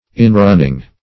\In"run`ning\